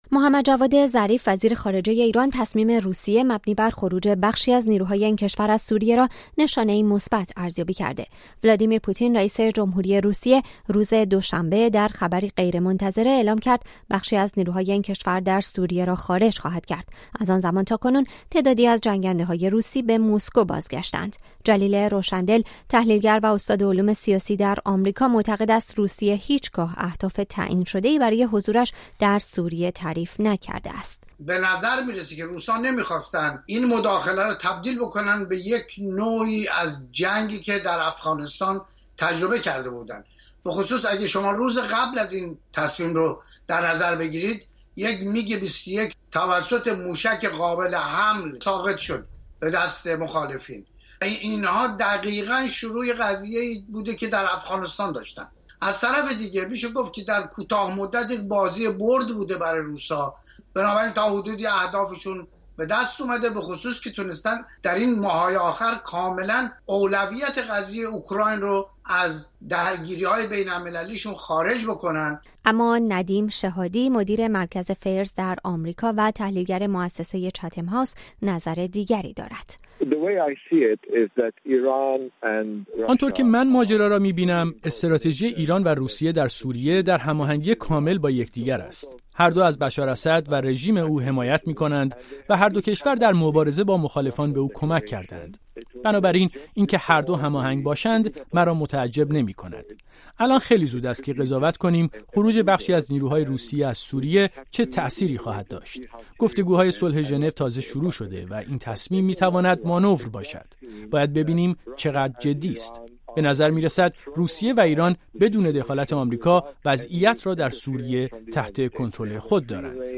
گزارش رادیویی درباره واکنش ایران و اسراییل به خروج بخشی از نیروهای روسیه از سوریه